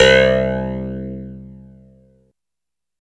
27 CLAV C2-L.wav